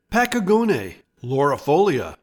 Pronounciation:
Pa-key-GO-ne lar-i-FOL-ee-a